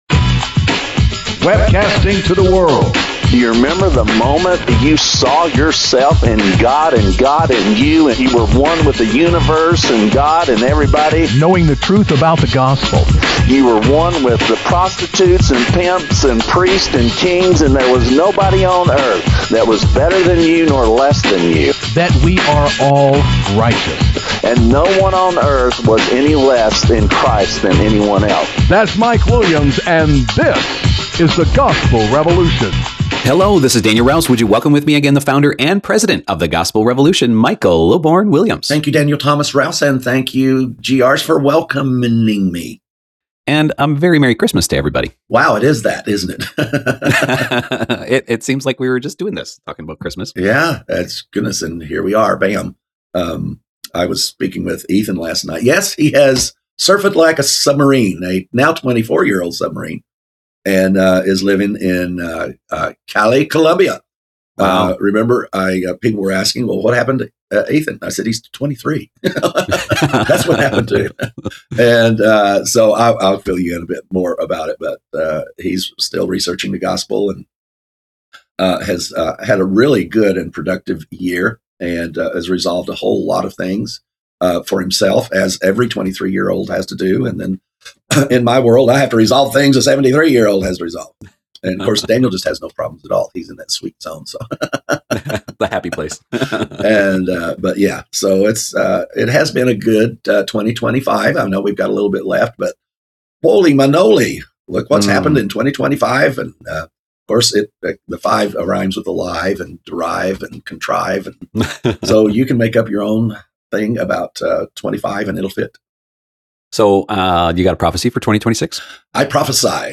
1 Become a Beast | David Goggins Powerful Motivation Speech 1:07:26